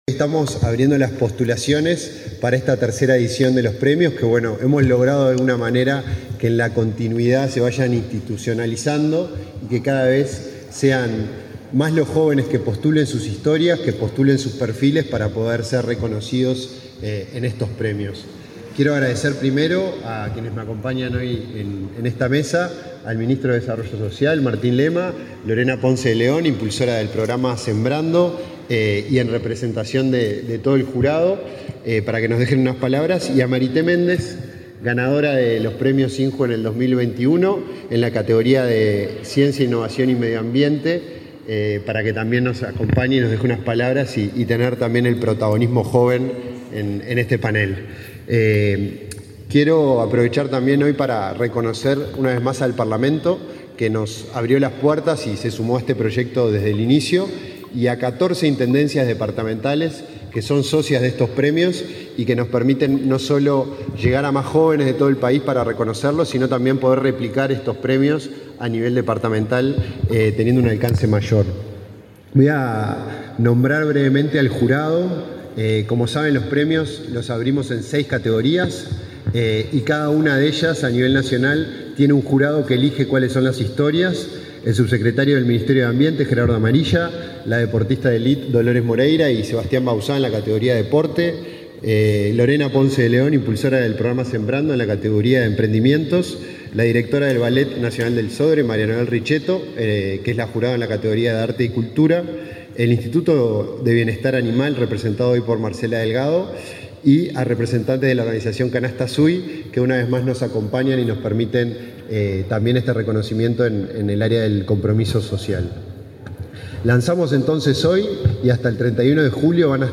Palabras de autoridades en lanzamiento de Premios INJU 2022